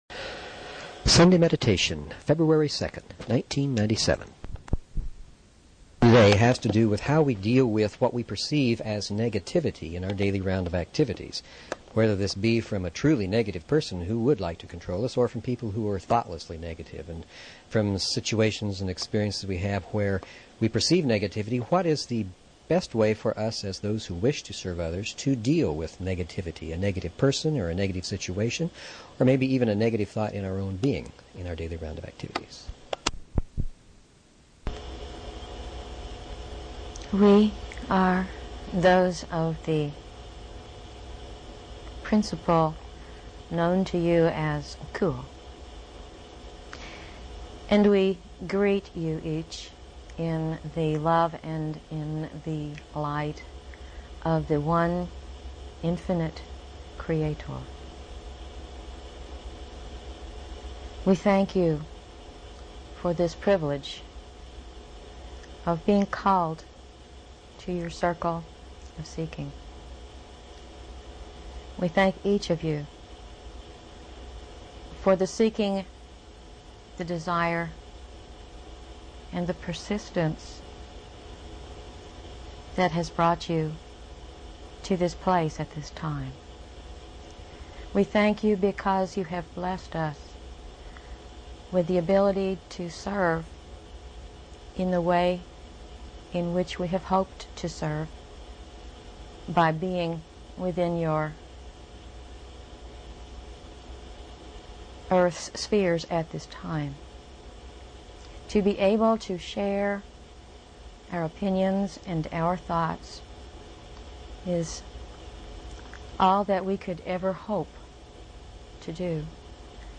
/llresearchquocommunications#archives Paranormal Philosophy Physics & Metaphysics Spiritual Medium & Channeling 0 Following Login to follow this talk show LL Research Quo Communications